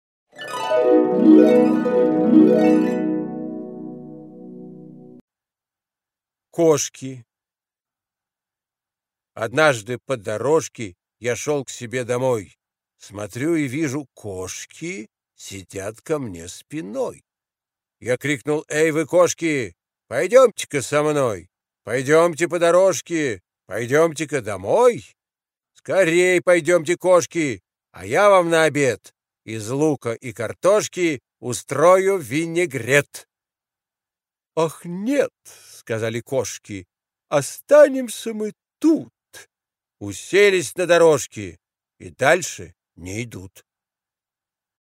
Harms-Koshki-chitaet-V.Smehov-stih-club-ru.mp3